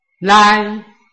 拼音查詢：【饒平腔】lai ~請點選不同聲調拼音聽聽看!(例字漢字部分屬參考性質)